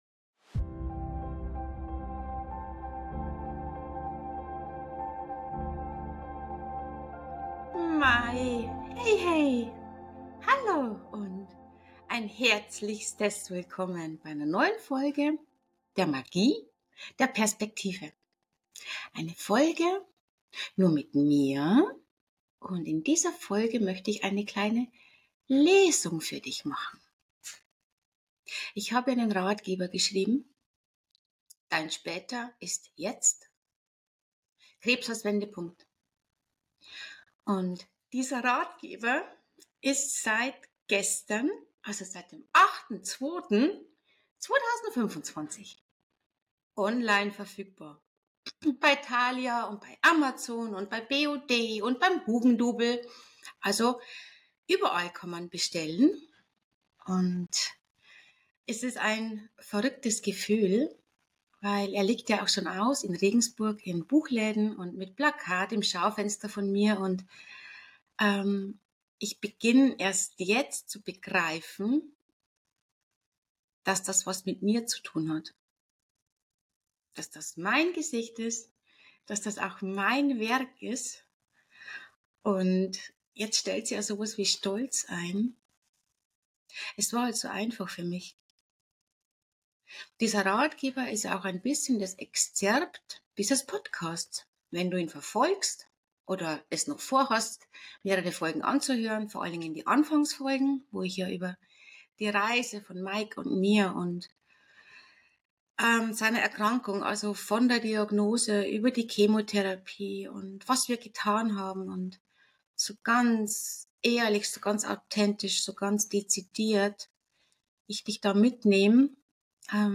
Lesung.m4a